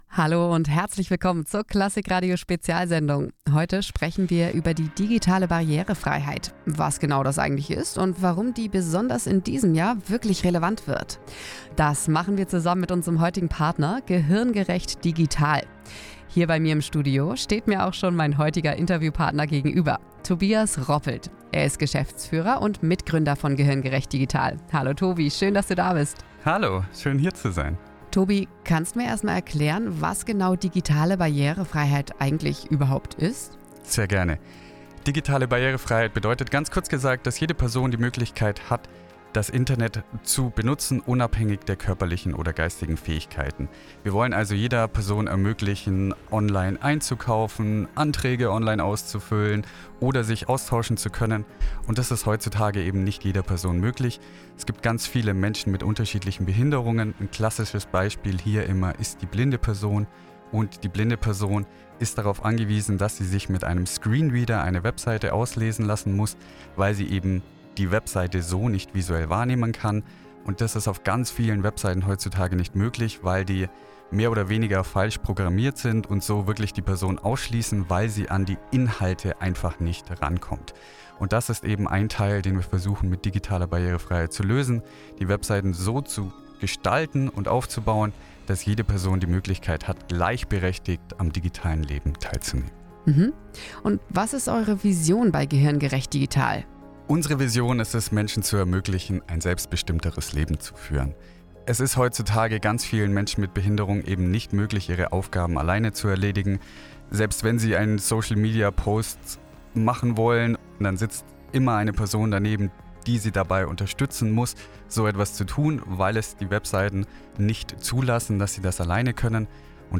Wir waren zu Gast in der Klassik-Radio-Spezial-Sendung über die digitale Barrierefreiheit.
Interview